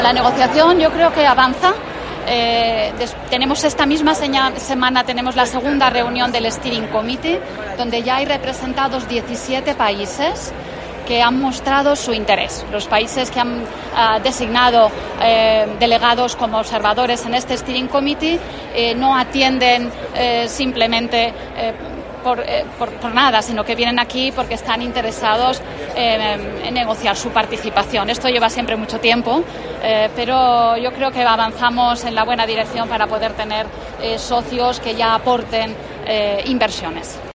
Así lo ha trasladado este lunes a preguntas de los periodistas la secretaria general de Investigación del Ministerio de Ciencia e Innovación, Raquel Yotti, quien ha participado en la inauguración del congreso internacional ICFRM-21.